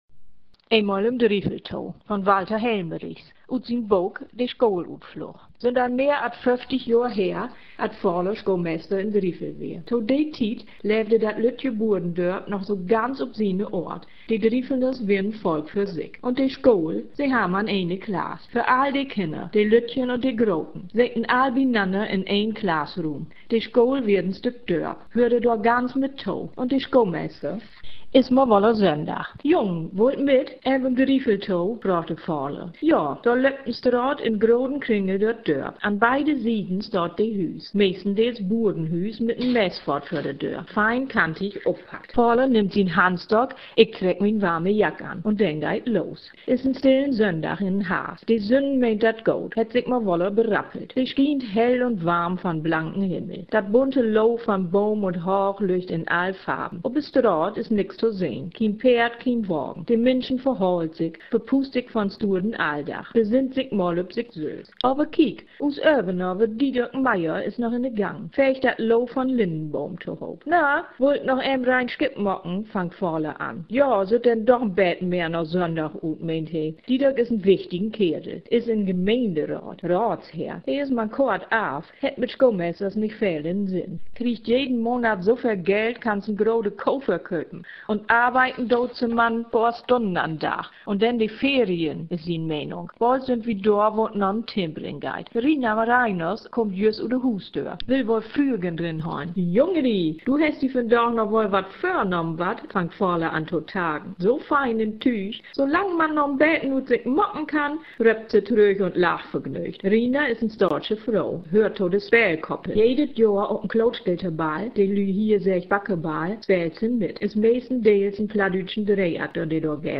Leseprobe aus den Buch  " De Schoolutflug "